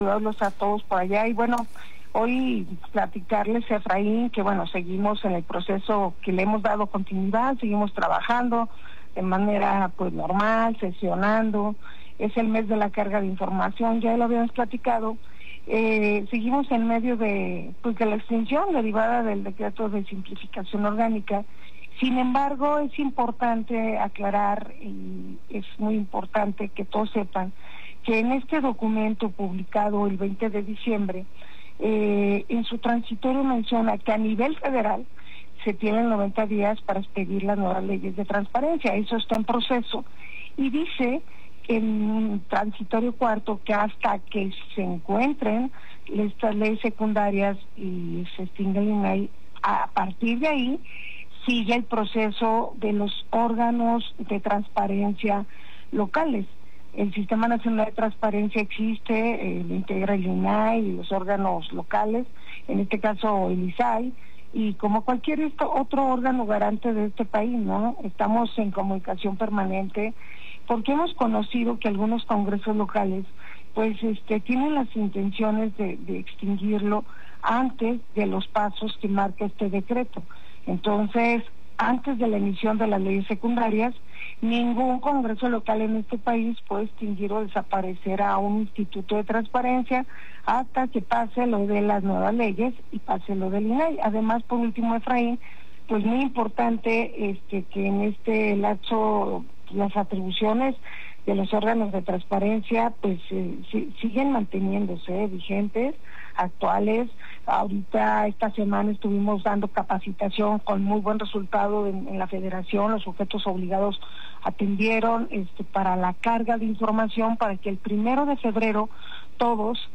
En Radio Alegría hablo sobre el proceso de extinción del INAI y los organismos garantes locales, incluyendo el IZAI.